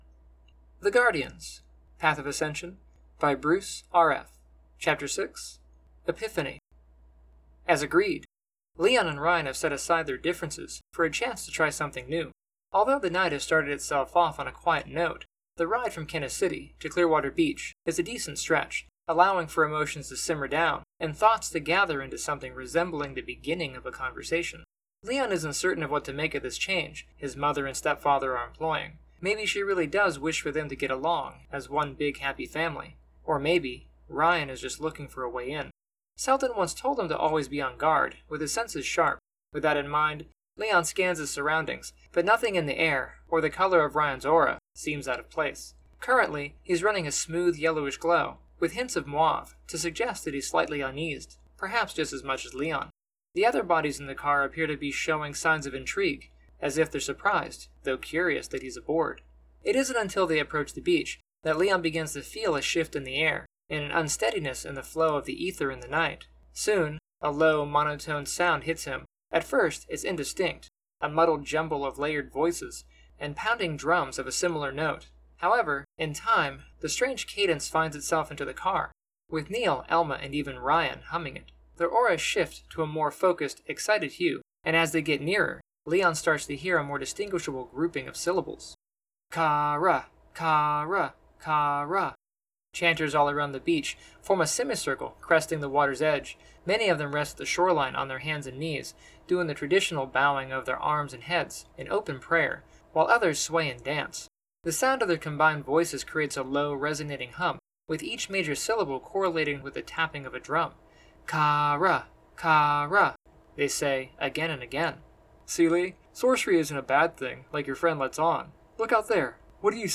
The Guardians: Path of Ascension - Audiobook